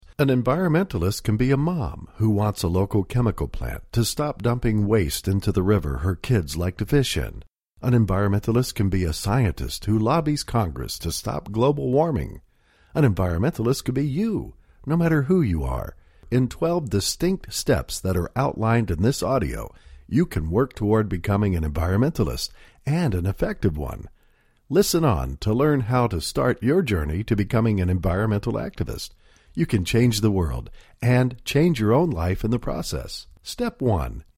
Twelve Easy Steps to Become a Highly Effective Environmentalist Audio Book
Twelve Easy Steps to Become a Highly Effective Environmentalist is an audio book that will guide you to become a voice of reason capable of standing out in the chorus of environmental destruction.